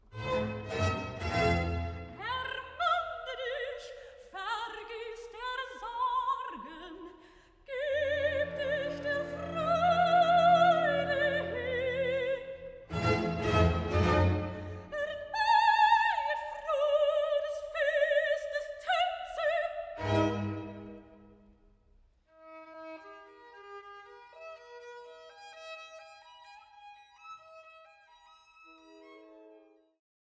Rezitativ (Chamital)